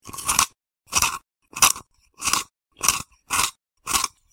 Für das heutige Adventsgewinnspiel suchen wir "Das mysteriöse Geräusch"...